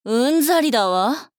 大人女性│女魔導師│リアクションボイス│商用利用可 フリーボイス素材 - freevoice4creators
怒る